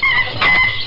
Chimp Sound Effect
Download a high-quality chimp sound effect.
chimp-1.mp3